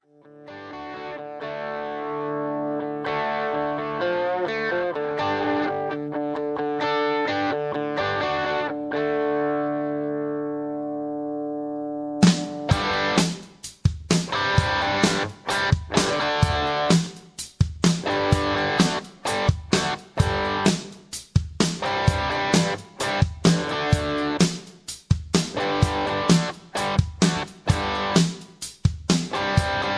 backing tracks
karaoke